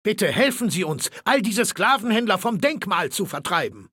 Datei:Maleold01 ms06 greeting 0005cbc5.ogg
Fallout 3: Audiodialoge